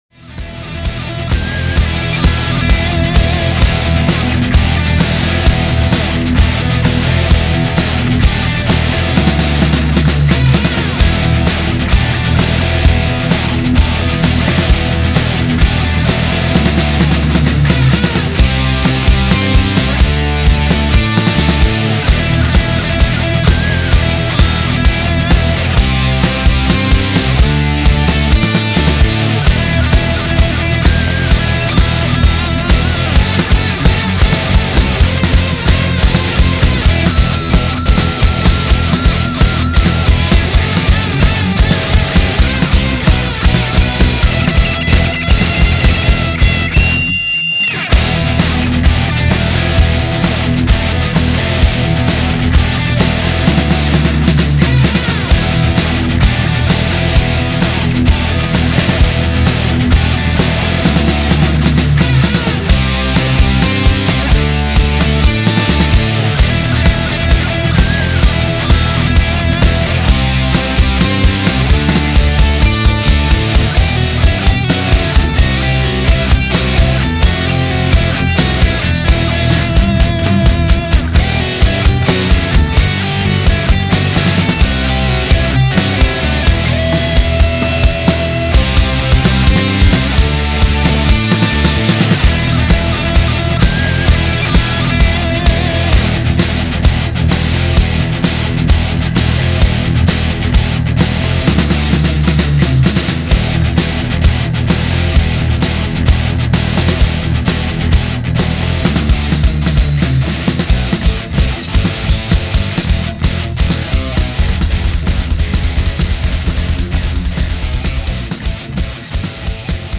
mix_met_r2.mp3, хоть и звучит не во всем приятно на слух, да и бочка в нем клипует, но в плане драйва и общей концепции звука лично мне нравится больше.